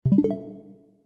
back-button-click.ogg